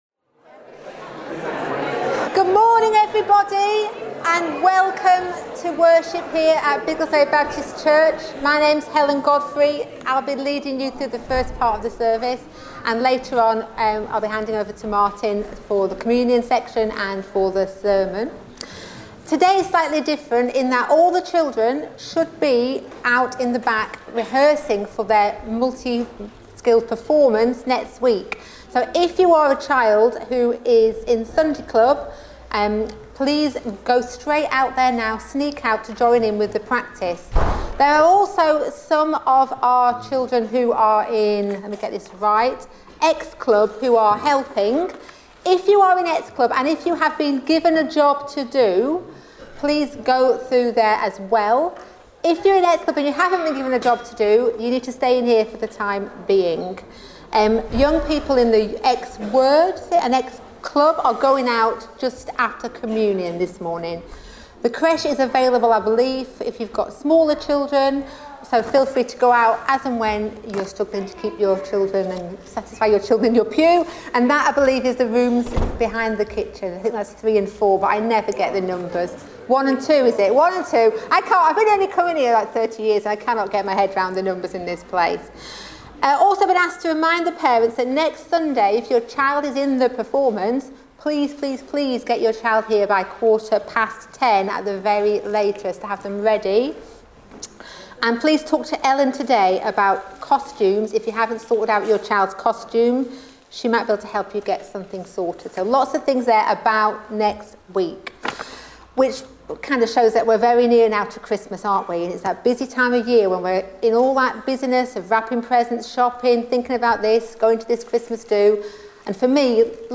14 December 2025 – Morning Communion Service
Service Type: Morning Service